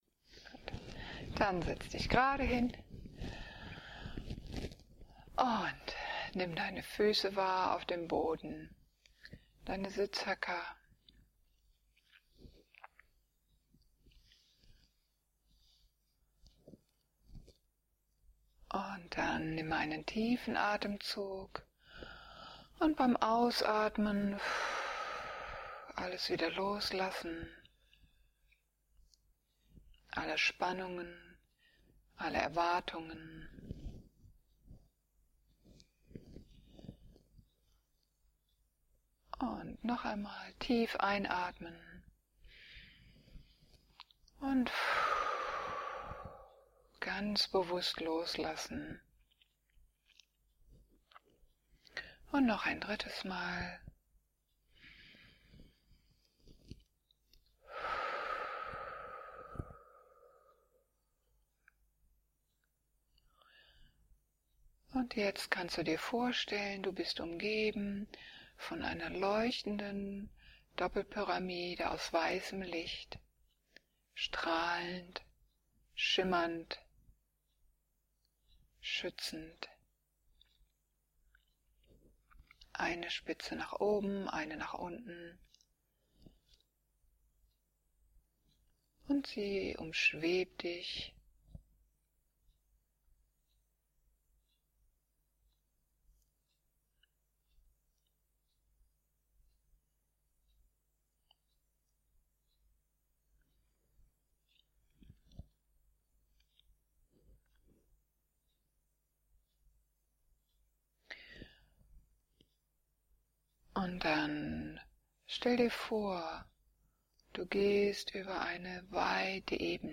2-08-meditation_begegnung_mit_der_weissen_buef.mp3